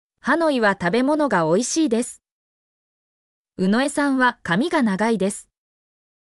mp3-output-ttsfreedotcom-49_6kNQsXLS.mp3